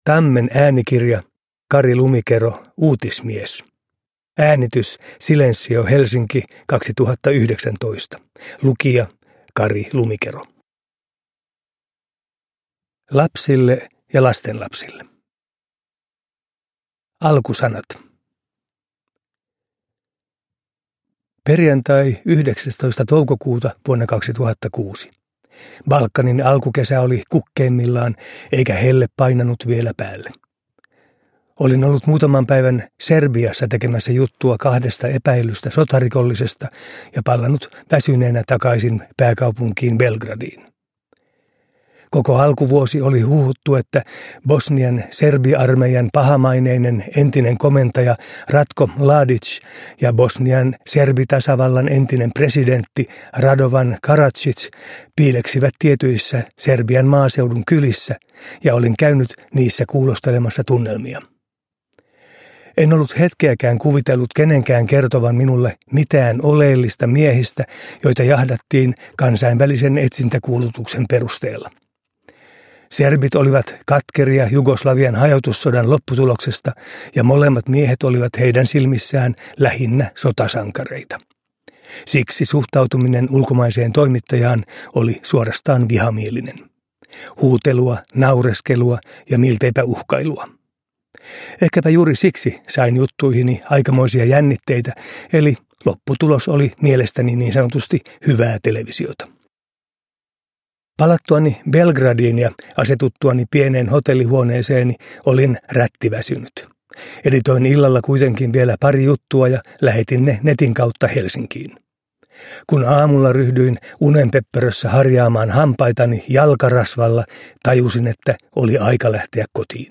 Uutismies – Ljudbok – Laddas ner